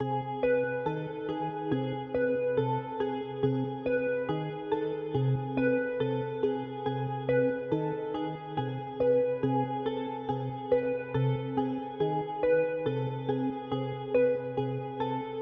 陷阱钢琴
描述：陷阱钢琴和合唱团
标签： 140 bpm Trap Loops Piano Loops 2.60 MB wav Key : Unknown FL Studio
声道立体声